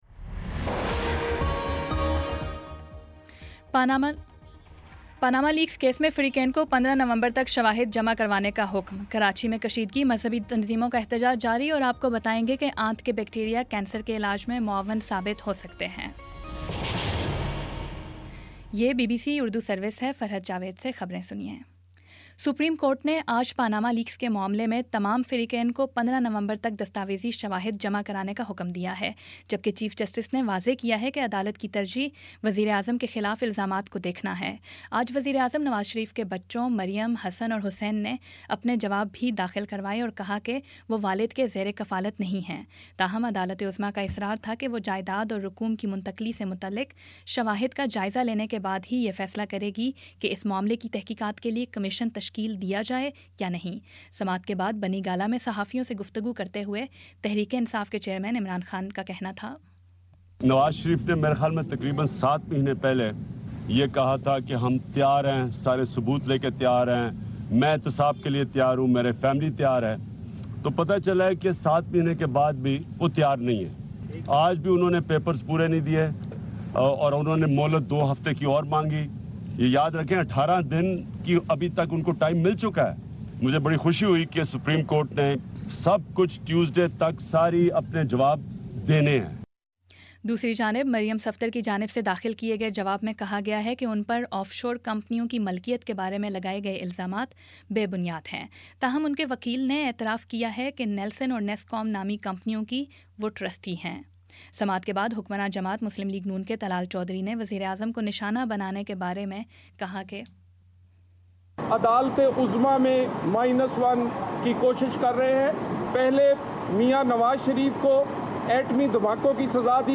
نومبر 07 : شام پانچ بجے کا نیوز بُلیٹن